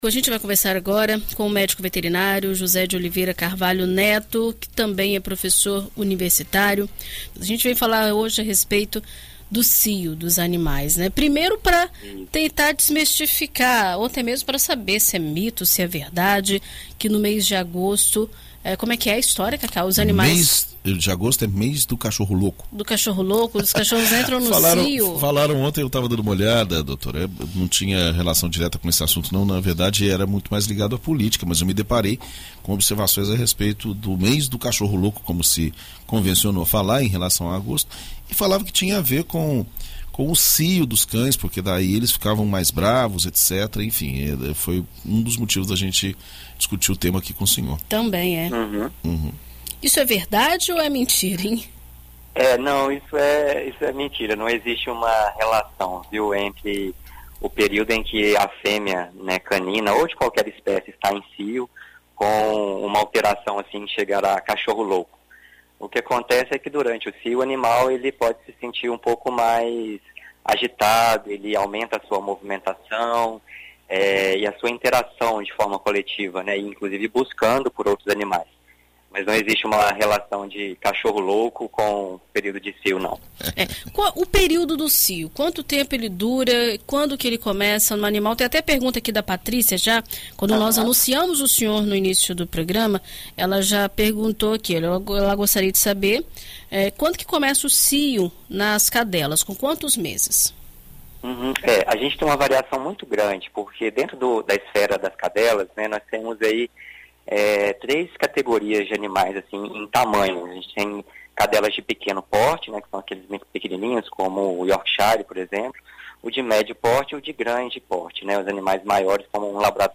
Em entrevista à BandNews FM Espírito Santo nesta quarta-feira (03)